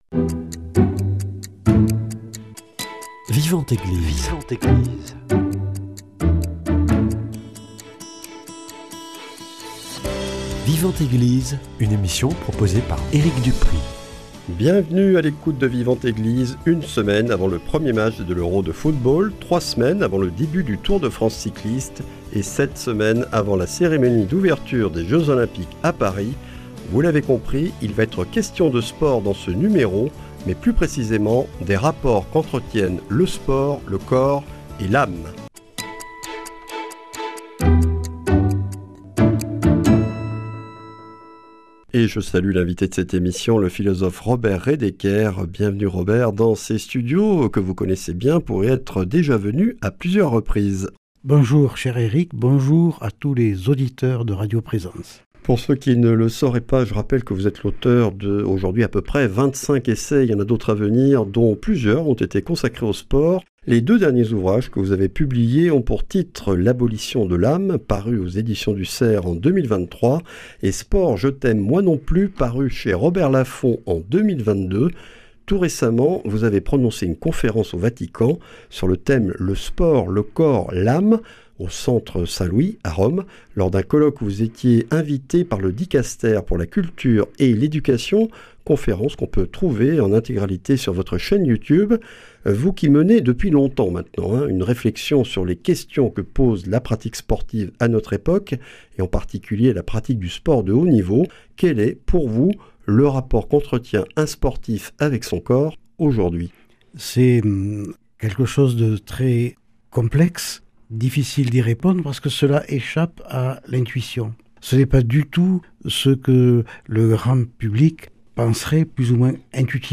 Le sport, le corps, l'âme... entretien